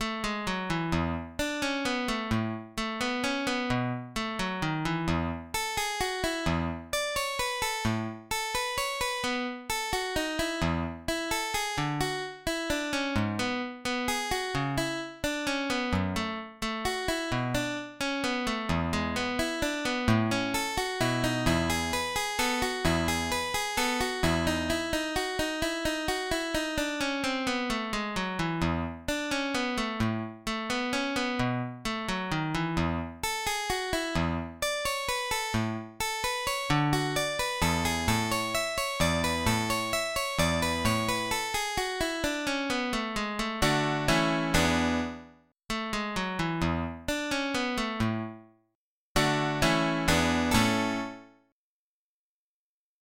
in La ****